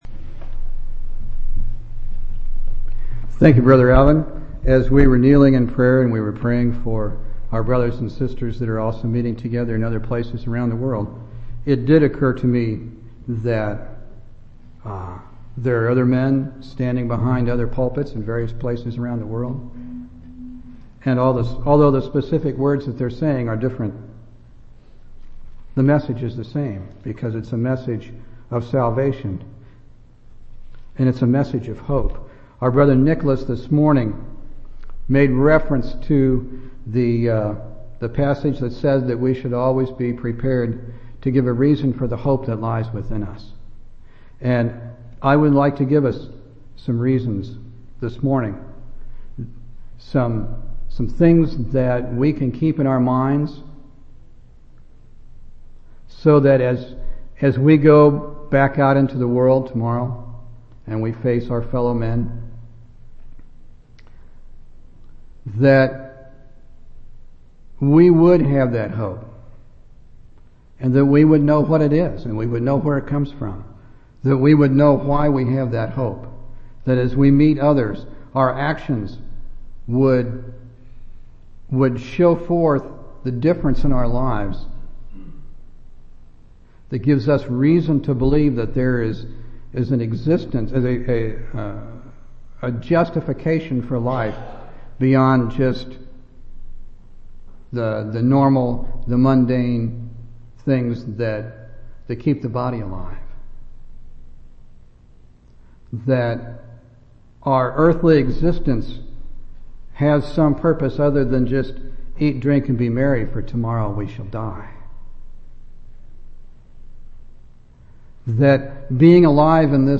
4/27/2003 Location: Temple Lot Local Event